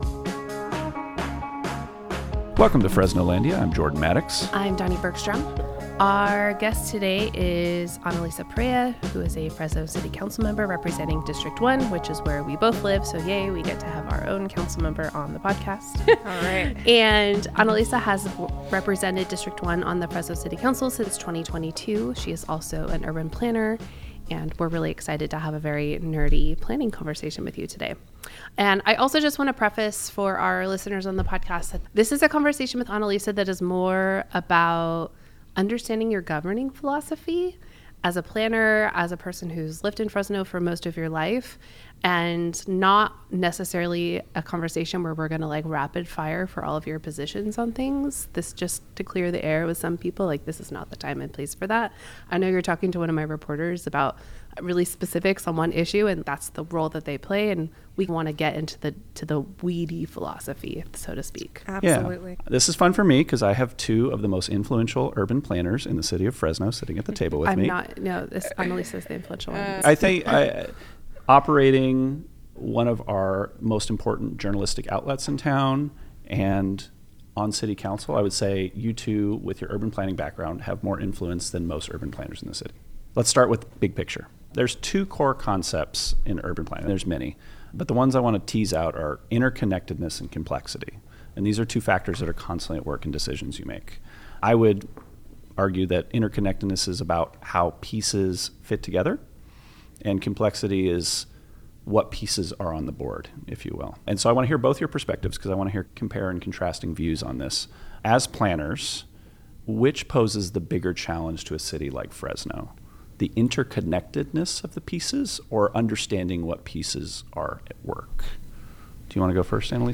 talk with Fresno City Councilmember and urban planner Annalisa Perea about how planning shapes the way she approaches municipal governance in Fresno. They discuss housing and zoning challenges, regional cooperation, the balance between growth and equity, and what smarter, denser development could look like for the city.